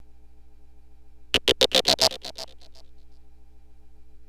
Track 15 - Synth 02.wav